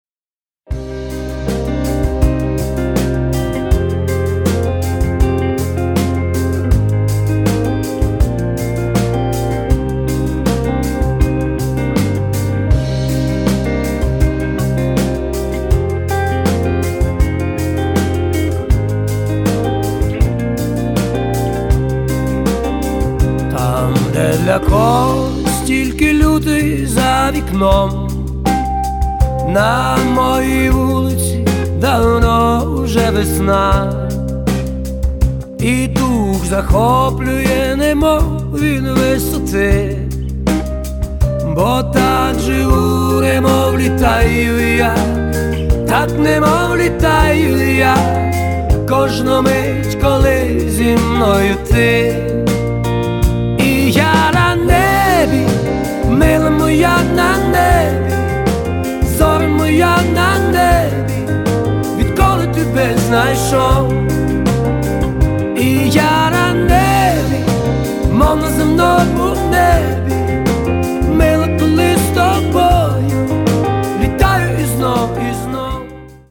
• Качество: 192, Stereo
красивые